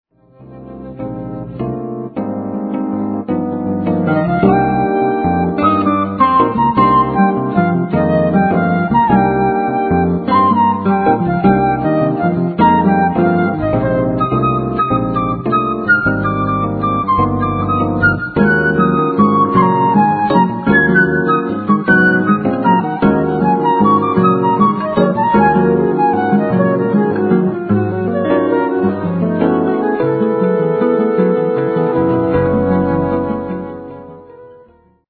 Este material fue grabado en estudio
Flauta traversa, Didgeridoo y Kalimba
Piano y Gruitarra.
Gruitarra y Charango.